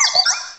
Cri de Tiplouf dans Pokémon Diamant et Perle.